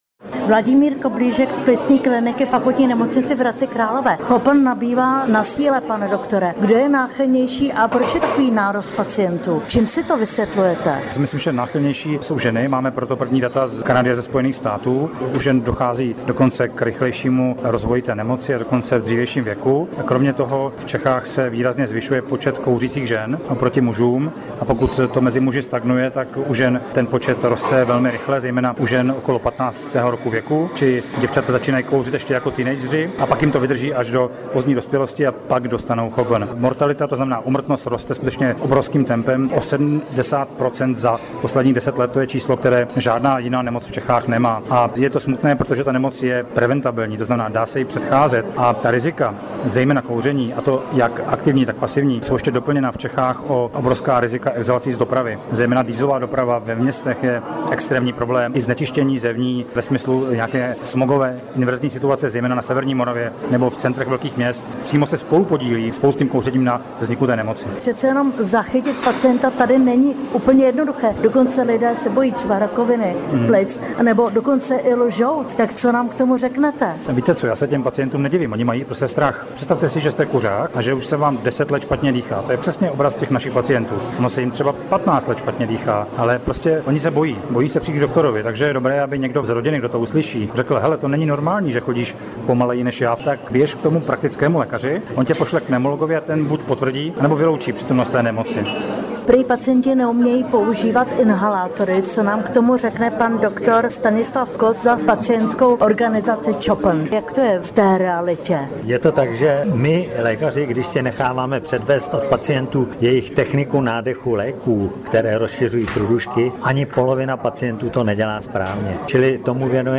Rozhovor s odborníky o Chronické obstrukční plicní nemoci (CHOPN)